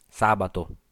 Ääntäminen
Ääntäminen US : IPA : [ˈsæt.ɚˌdeɪ] UK UK : IPA : /ˈsætədeɪ/ IPA : /ˈsætədi/ Tuntematon aksentti: IPA : /ˈsætɚdeɪ/ IPA : /ˈsætɚdi/ IPA : /ˈsæt.ɚˌdi/ IPA : /ˈsæt.əˌdeɪ/ IPA : /ˈsæt.əˌdi/ Lyhenteet ja supistumat Sa Sat